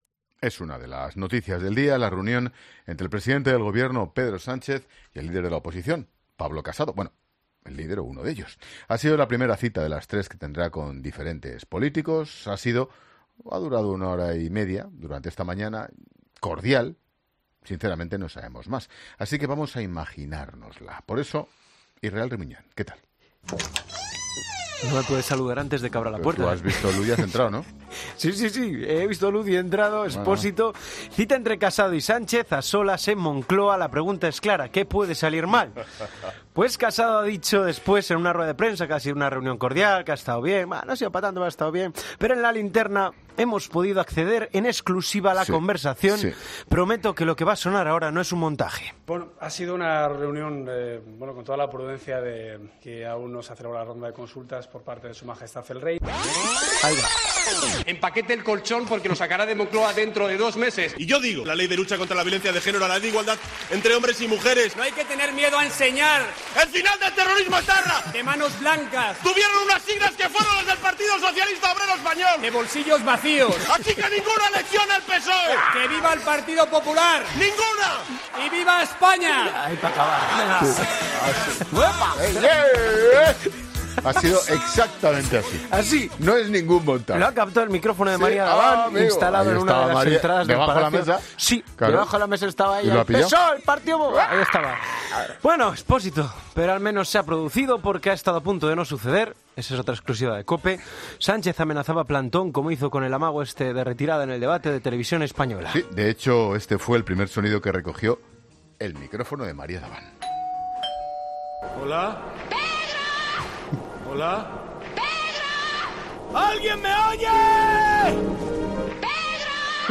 Porque todo, hasta lo más serio, tiene su versión alternativa y, por qué no, surrealista.